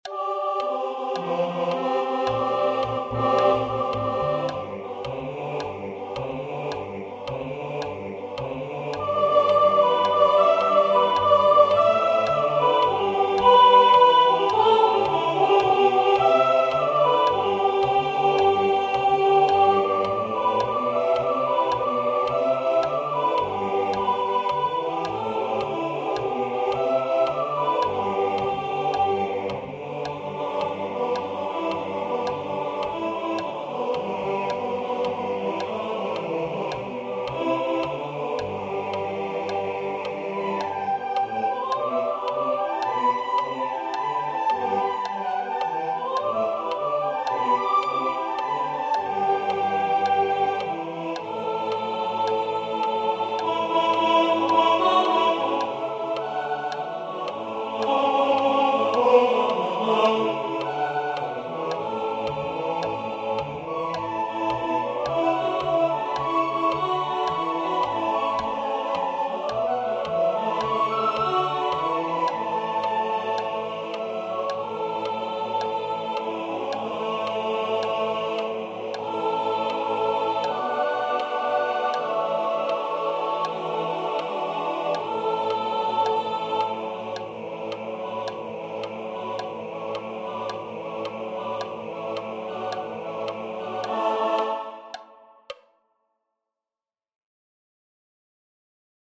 This page contains rehearsal files for choir members.
Solos